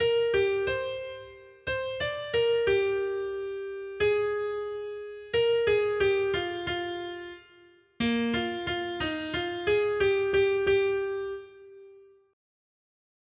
Noty Štítky, zpěvníky ol21.pdf responsoriální žalm Žaltář (Olejník) 21 Ž 146, 1-10 Skrýt akordy R: Pane, přijď, a zachraň nás! 1.